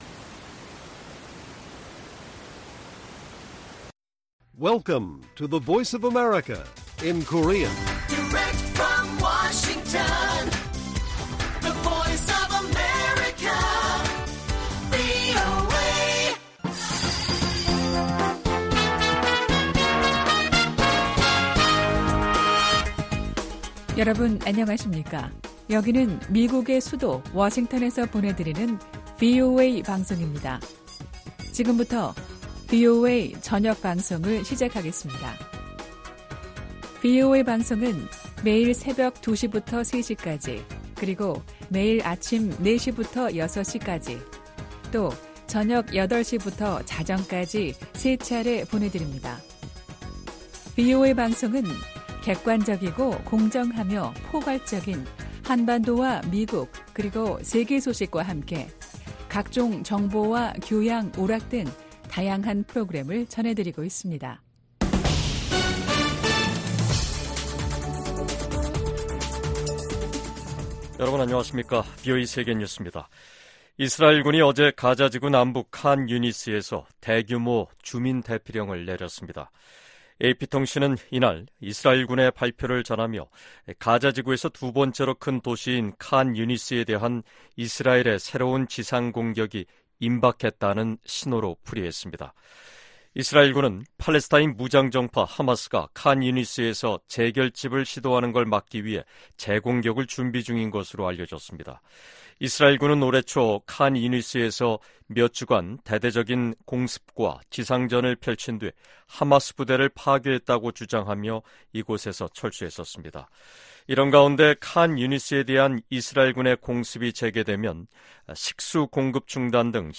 VOA 한국어 간판 뉴스 프로그램 '뉴스 투데이', 2024년 7월 2일 1부 방송입니다. 지난해 10월 7일 하마스의 이스라엘 공격 당시 피해를 입은 미국인들이 북한 등을 상대로 최소 40억 달러에 달하는 손해배상 소송을 제기했습니다. 북한은 어제(1일) 초대형 탄두를 장착하는 신형 전술탄도미사일 시험발사에 성공했다고 밝혔습니다.